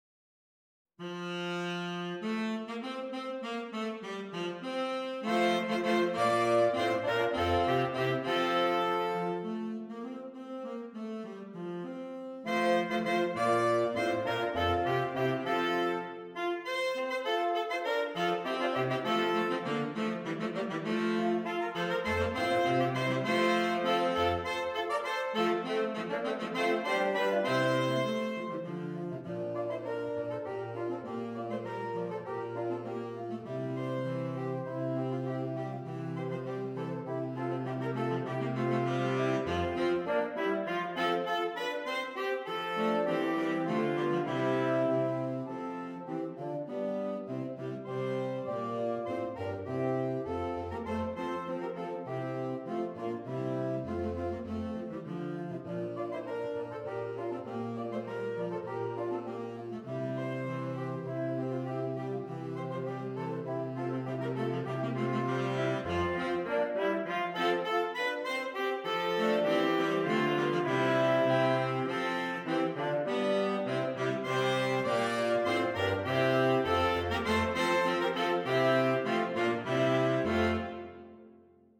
Saxophone Quartet (AATB)
English madrigal